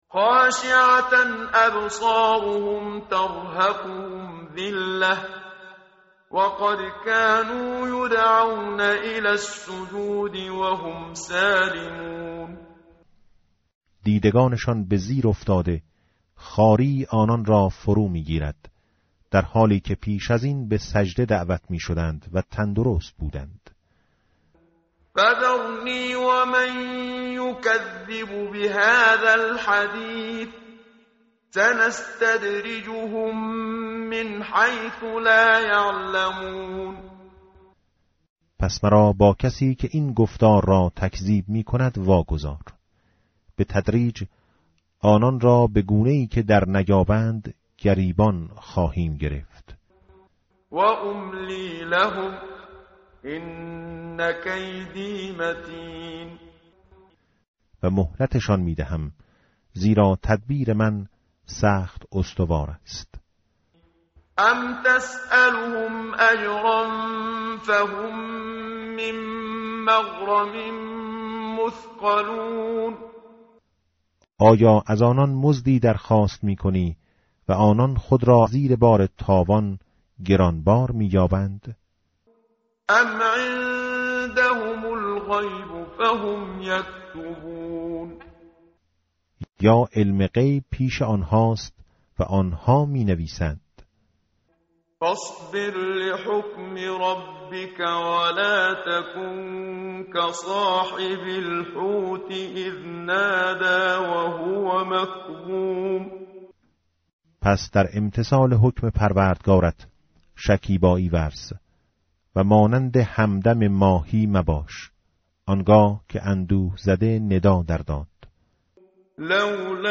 tartil_menshavi va tarjome_Page_566.mp3